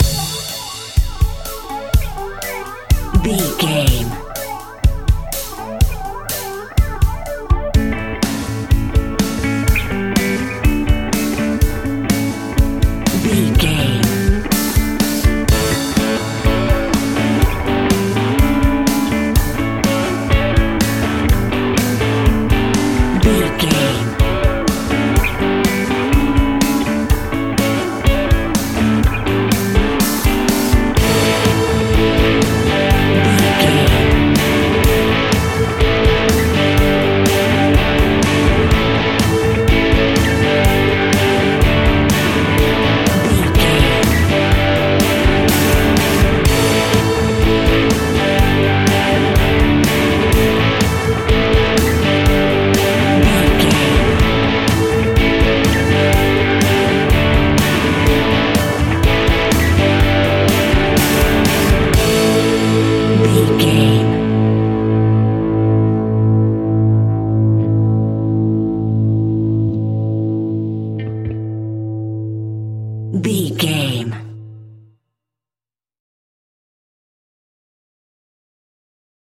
Uplifting
Mixolydian
rock
hard rock
blues rock
distortion
instrumentals
Rock Bass
heavy drums
distorted guitars
hammond organ